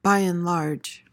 PRONUNCIATION:
(by uhn LARJ)